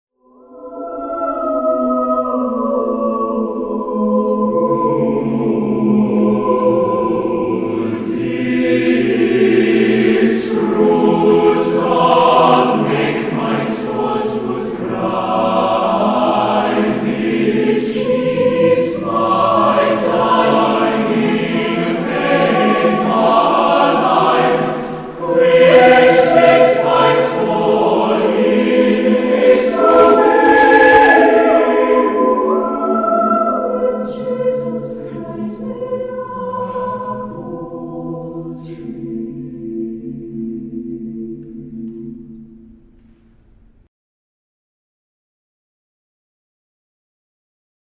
• Voicing: satb
• Accompaniment: a cappella
• Other: 2010, sacred